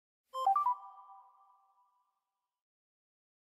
f1 notification Meme Sound Effect
f1 notification.mp3